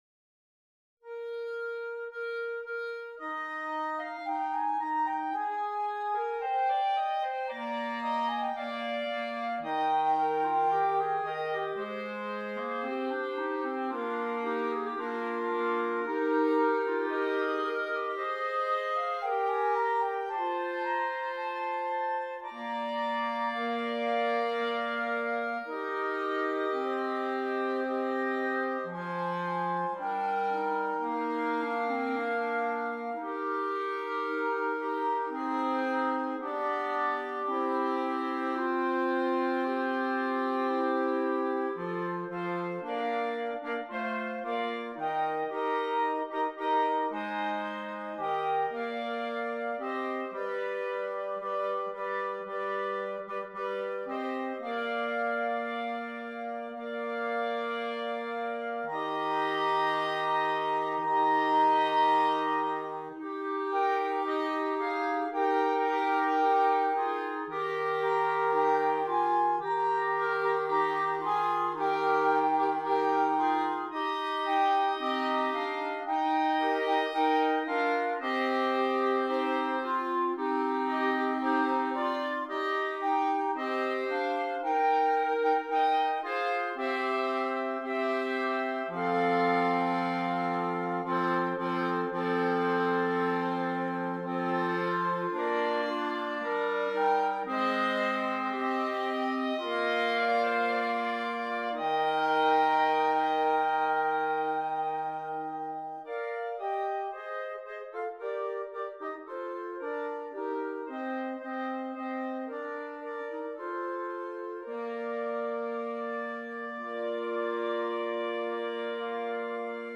5 Clarinets